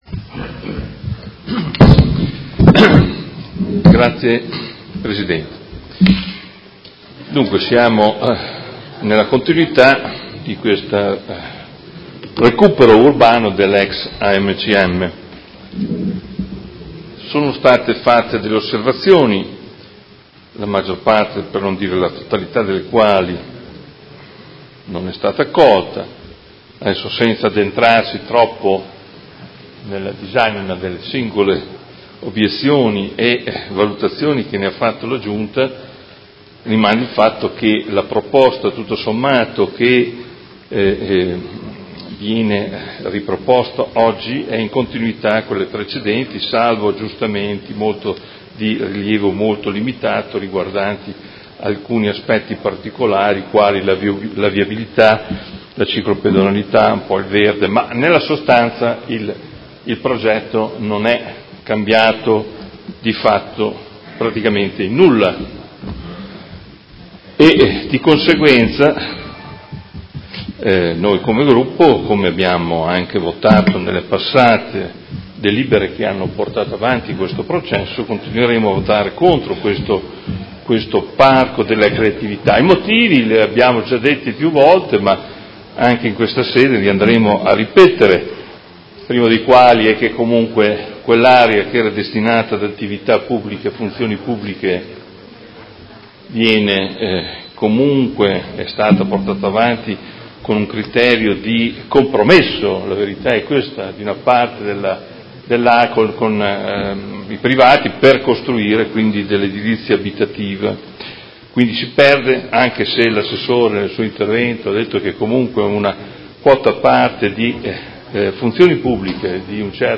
Adolfo Morandi — Sito Audio Consiglio Comunale
Seduta del 04/04/2019 Dibattito.